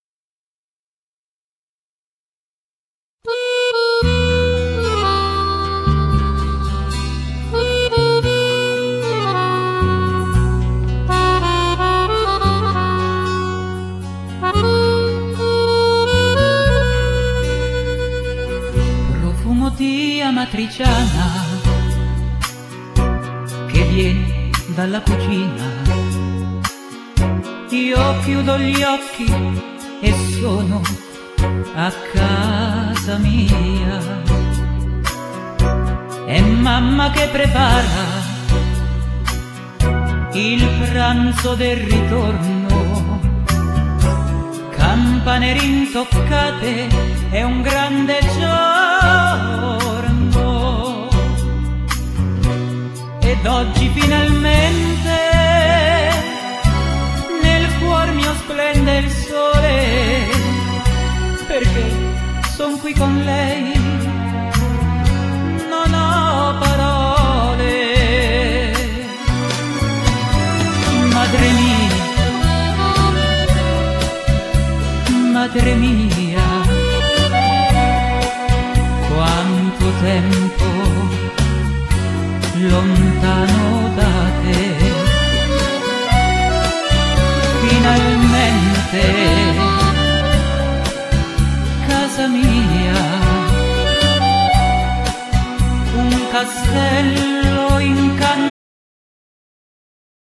Genere : Pop / Folk